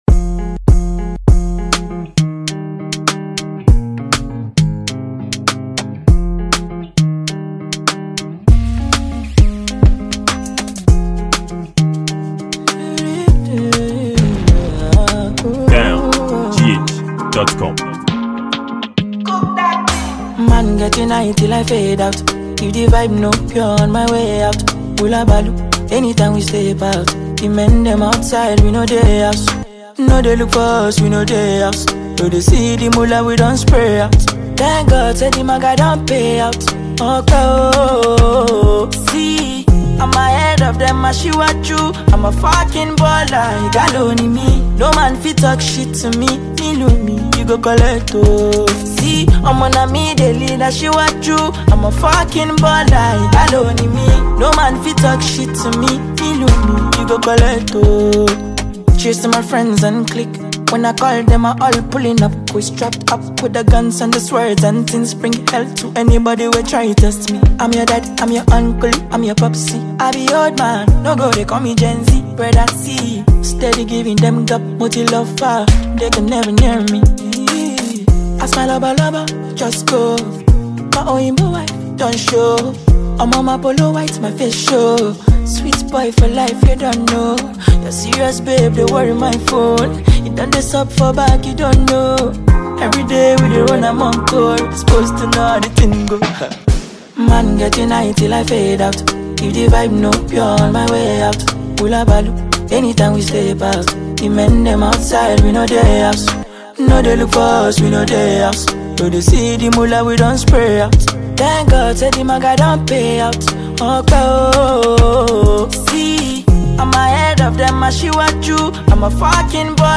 Top-notch Nigerian awarding singer and very skilled musician